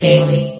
New & Fixed AI VOX Sound Files